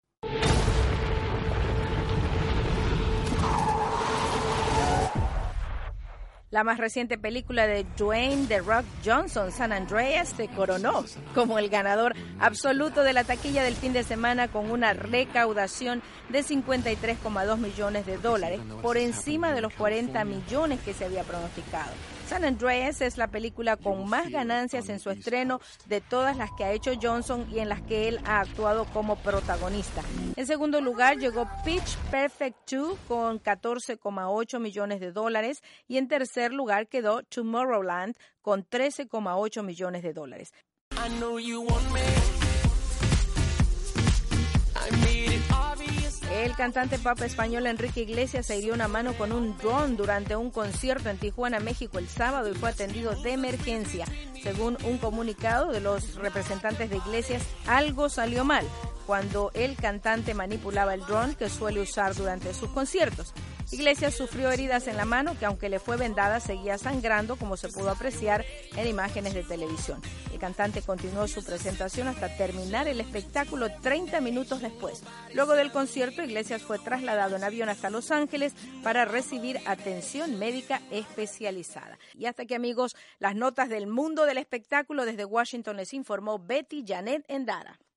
VOA: Noticias del Entretenimiento